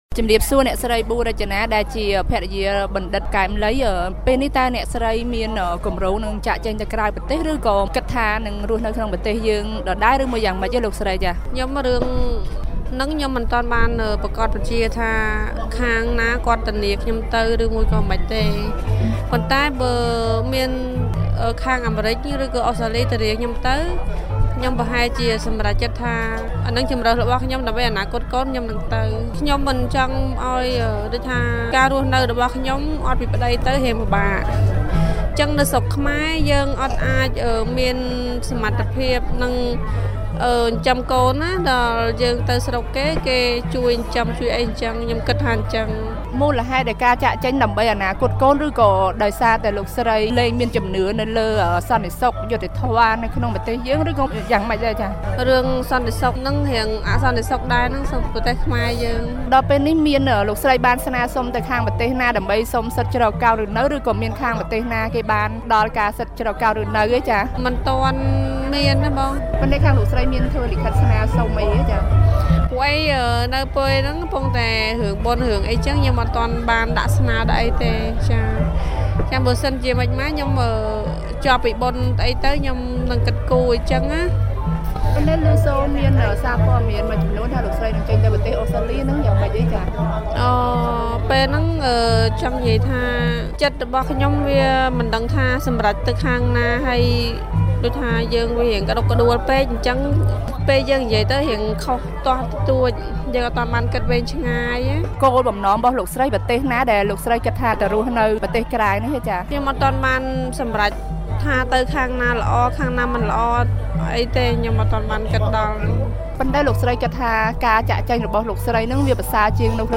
បទសម្ភាសន៍